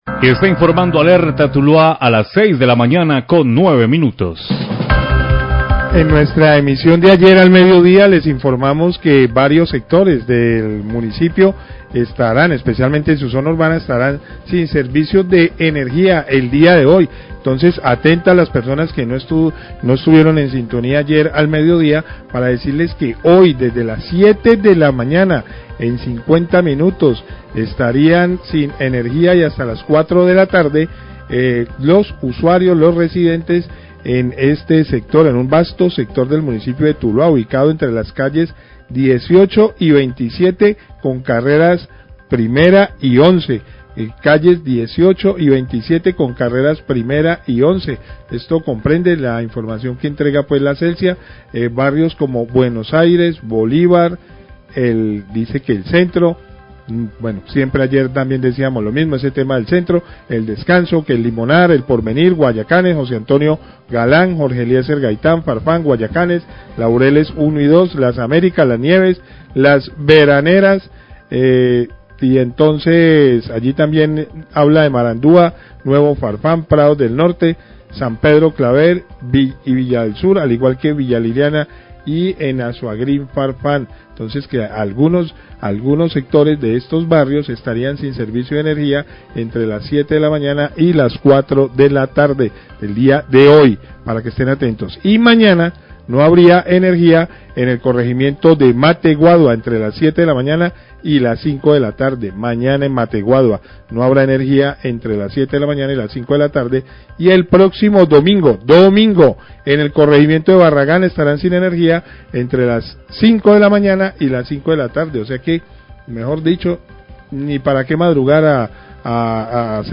Periodista recuerda los cortes programados de Celsia en Tuluá, Mateguadua y Barragán
Radio